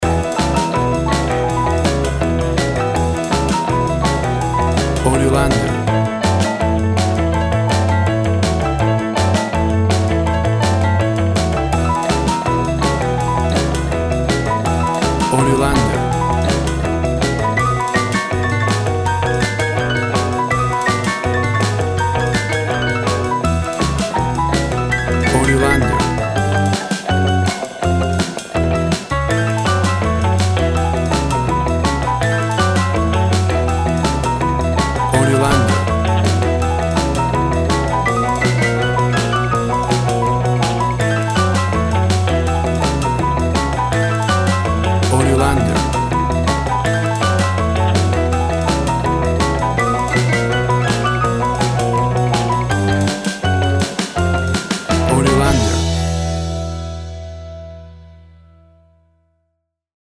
Sounds of blues and rock and roll from the 50’s.
Tempo (BPM) 160